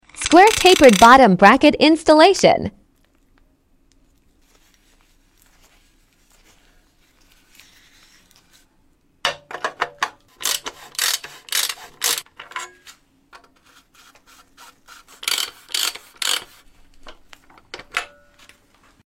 SQUARE TAPERED BOTTOM BRACKET INSTALLATION sound effects free download